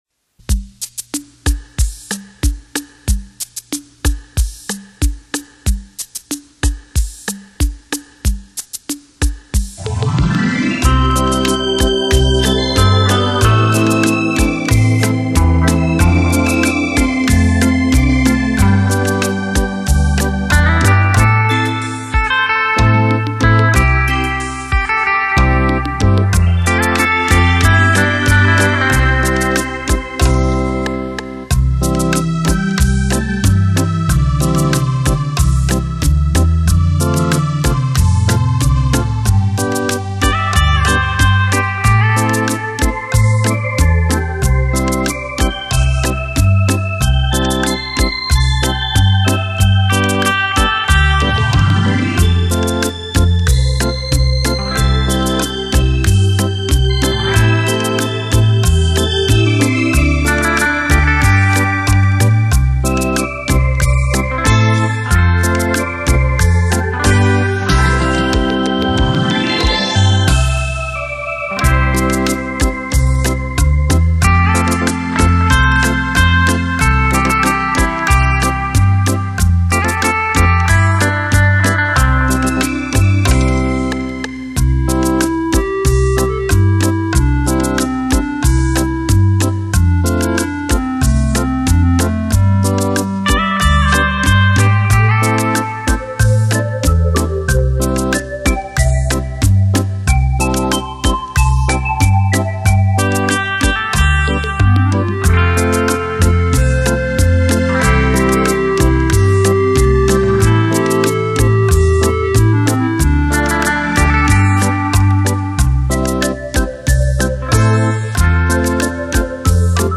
熟悉的旋律  优美的音乐  勾起我们绵绵依旧之情......